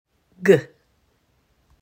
Here is a guide on how to pronounce the most common sound represented by each letter of the alphabet.
pronouncing letter sounds - g is for gate